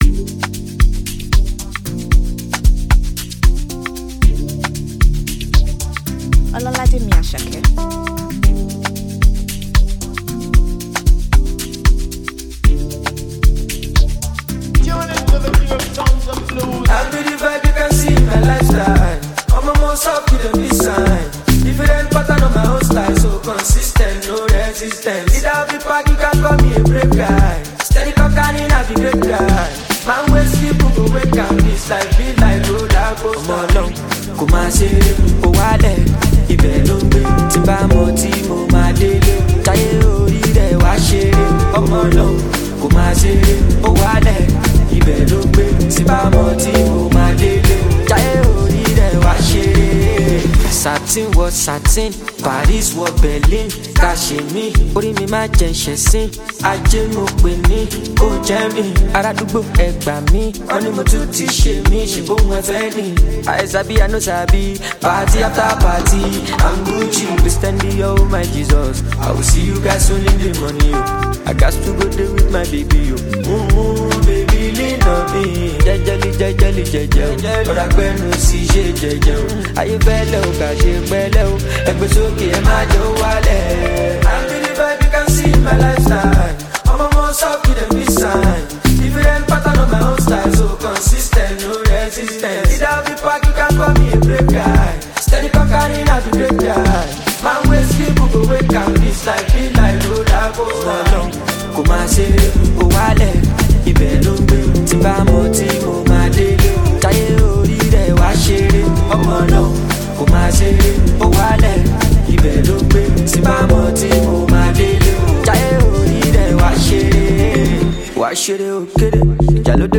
Well renowned Nigerian artist and performer
gbedu song